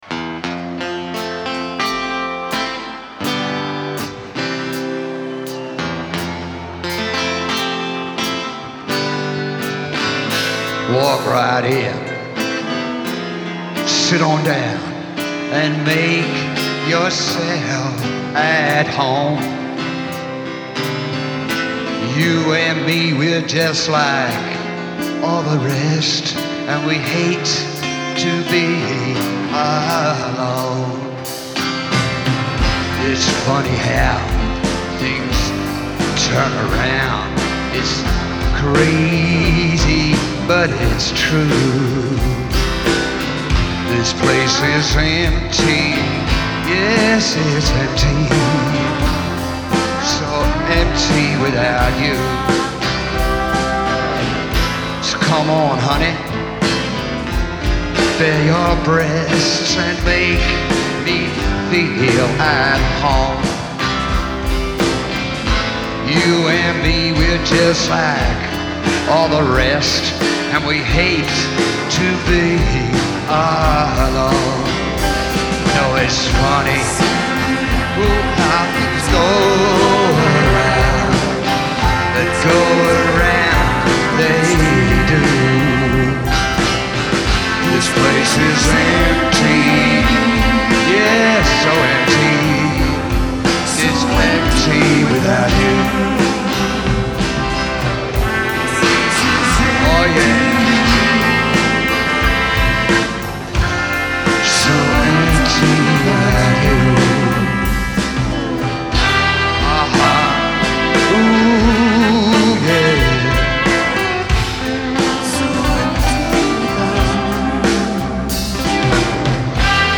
Genre : Rock